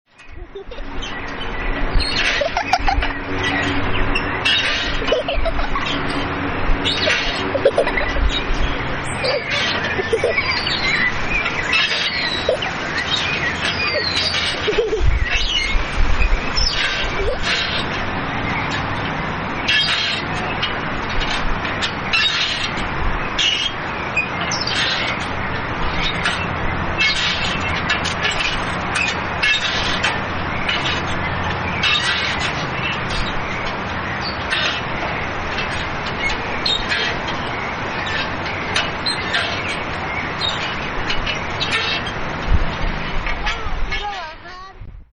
Columpio
Lugar: Tuxtla Gutierrez, Chiapas; Mexico.
Equipo: Grabadora Sony ICD-UX80 Stereo Fecha: 2013-01-31 23:28:00 Regresar al índice principal | Acerca de Archivosonoro